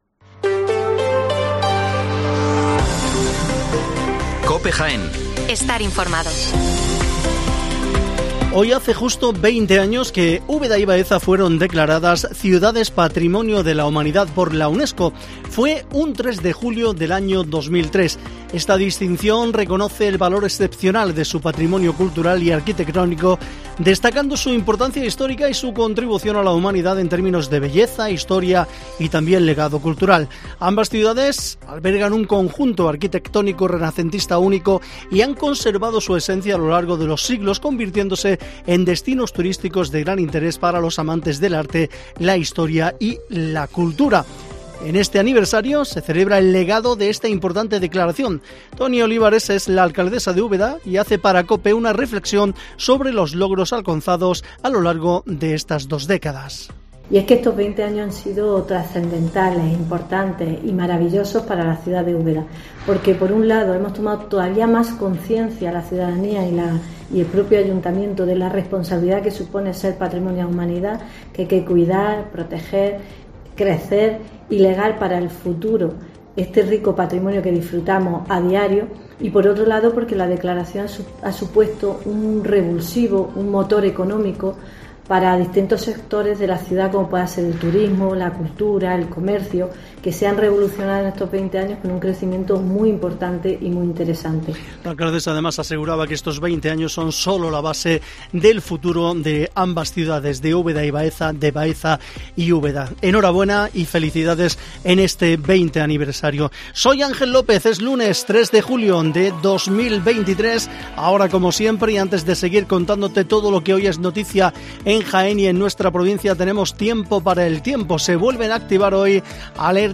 informativo Mediodía Jaén